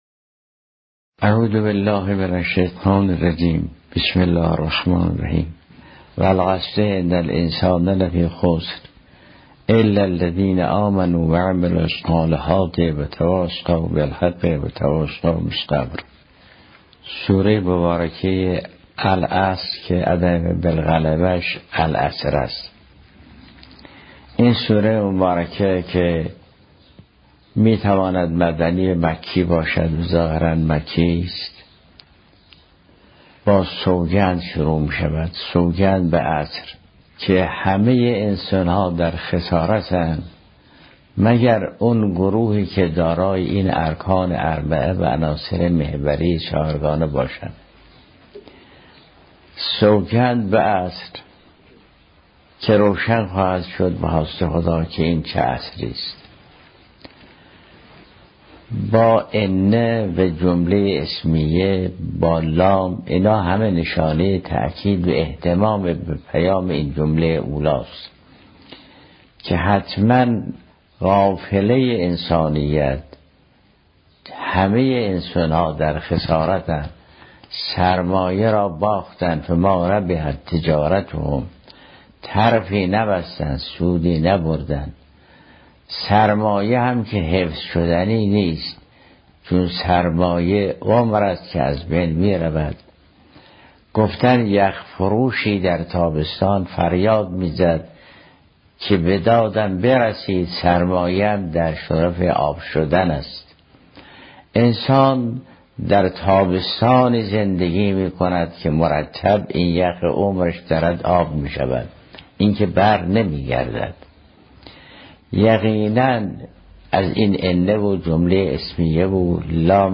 در سالروز میلاد کریمه اهل بیت فاطمه معصومه سلام الله علیها، و در بیست و سومین مجمع عمومی نشست دوره ای اساتید سطوح عالیه و خارج حوزه علمیه قم، حضرت آیت الله العظمی جوادی آملی، در جمع صدها تن از اساتید به ایراد سخن پرداختند.